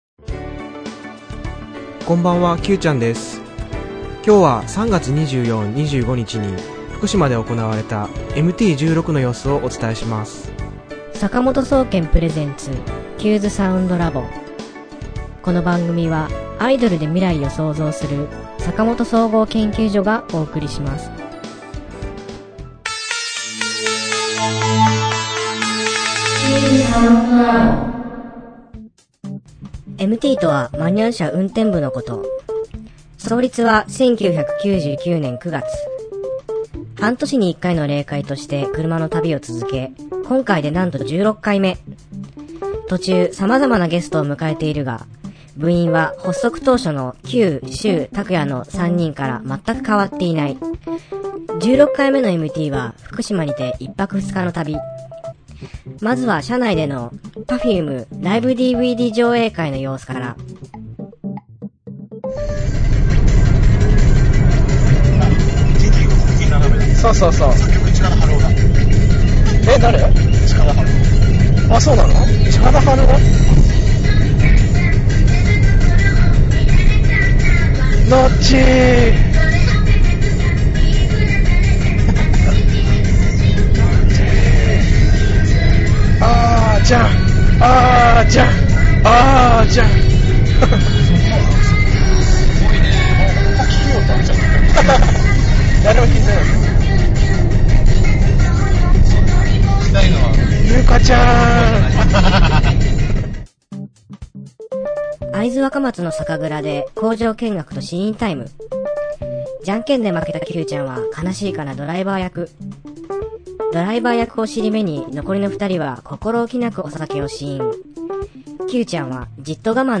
今週のテーマ：『MT16』レポート3/24,25に福島で行われたMT16の様子をポッドキャスティング（音声放送）でお伝えします。新しいジングルの収録にも挑戦しています！